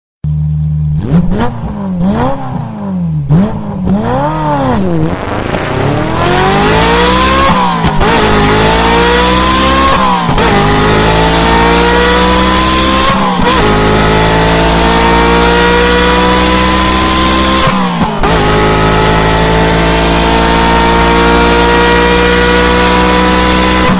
V8_Engine.mp3